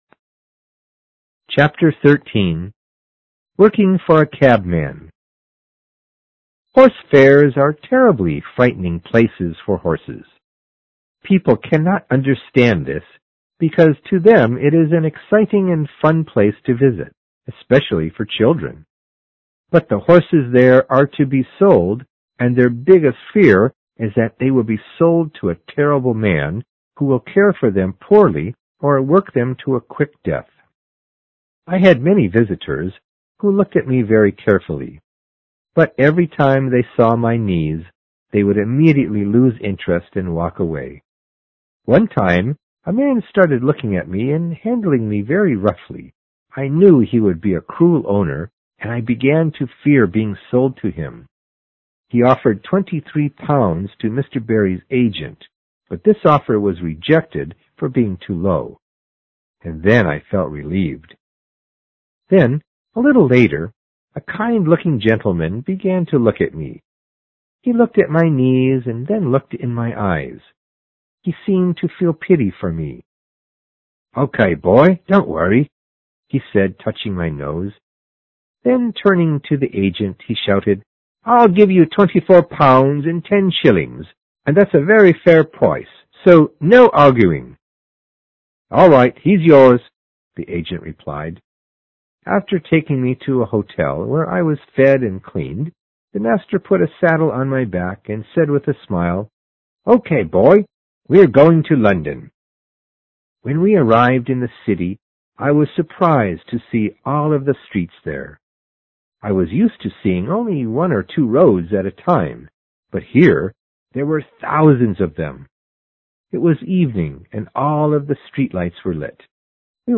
有声名著之黑骏马13 听力文件下载—在线英语听力室